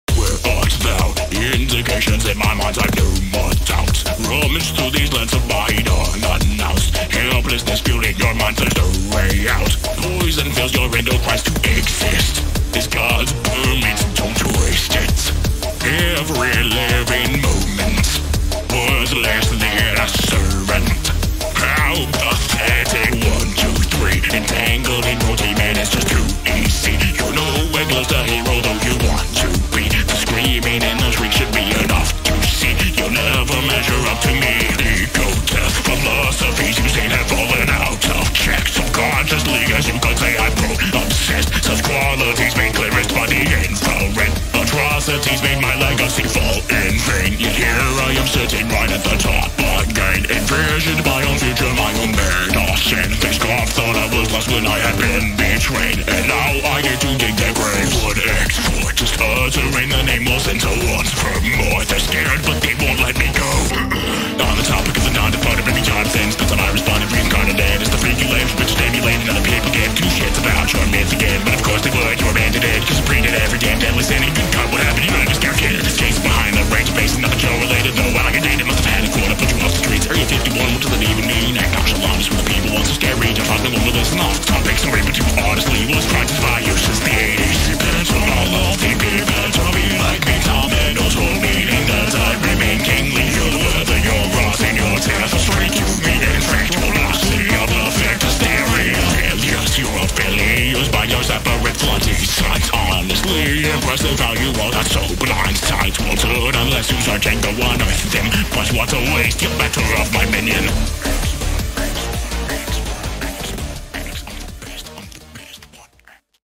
(со словами)